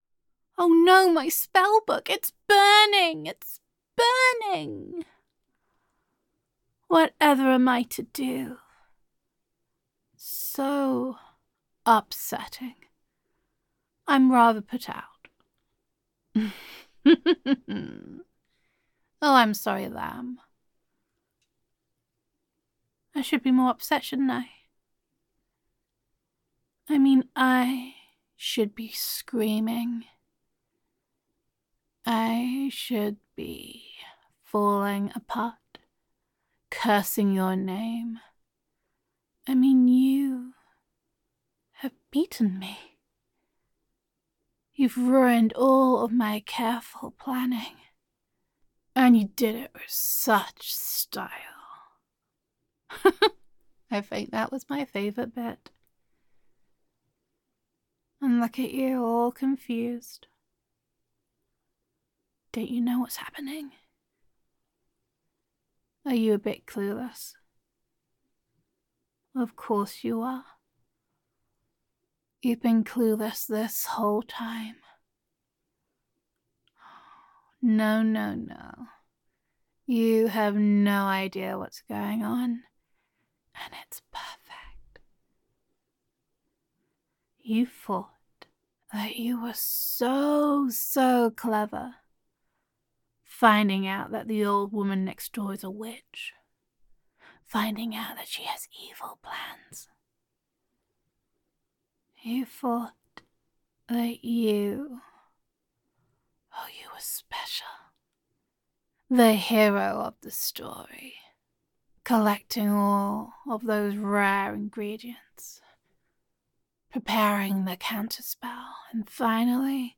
[F4A] Youth Is Wasted on the Young [Kindly Neighbourhood Grandma][The Witch Next Door][Casting Out the Witch][You Totally Know What You Are Doing][Gender Neutral][The Witch Next Door Totally Saw You Coming]